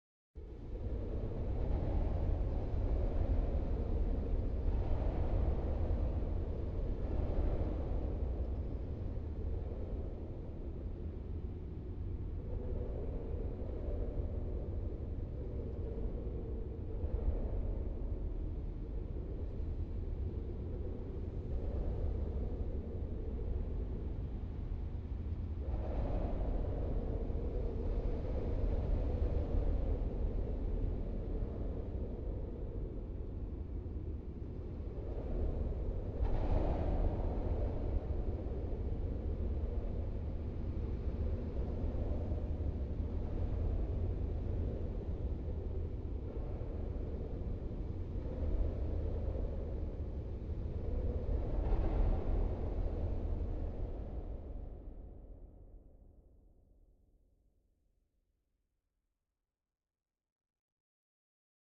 cave-wind.ogg